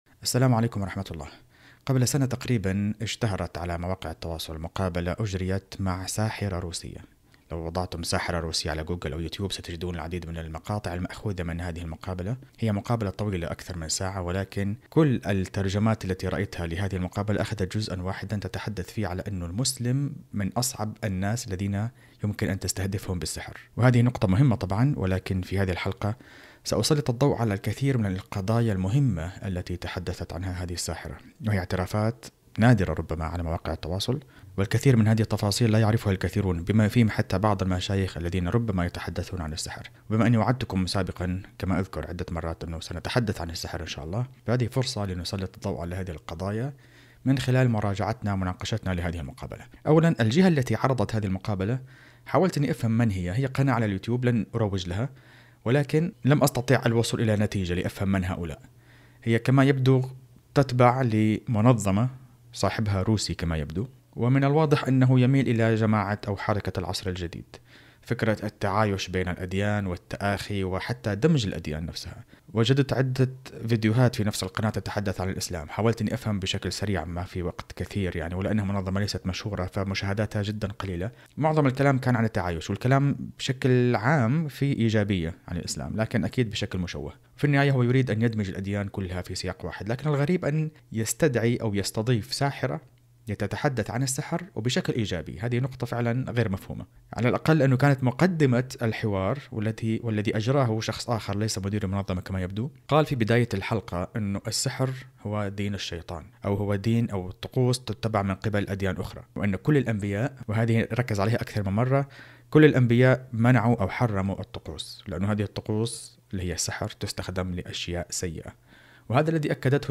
اخترنا مقابلة أجرتها منظمة "روحانية" مع ساحرة روسية